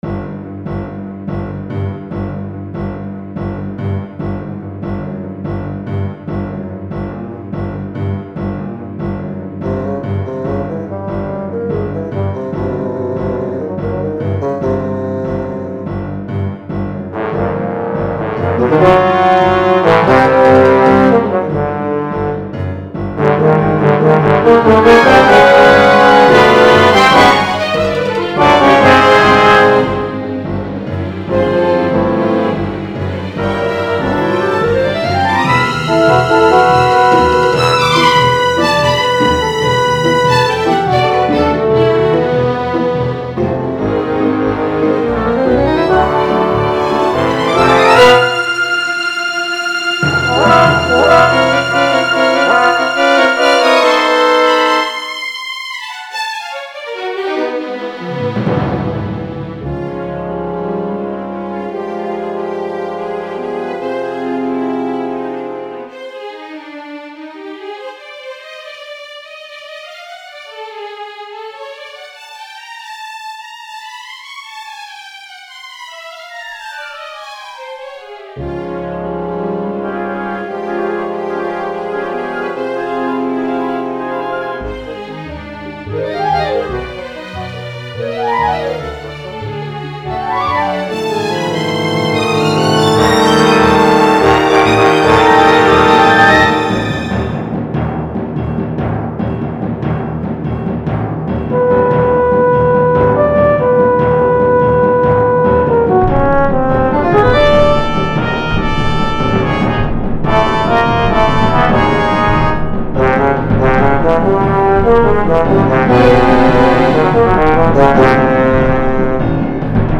It ends with an offstage horn playing a slightly-flat "G" (harmonic) and it is intended for this drone to form the basis of the next piece (played without pause).
It isn't quite complete (notice it is sparse around 1:10 with only violins playing).
-Three horns (including one offstage)
-Strings (the usual)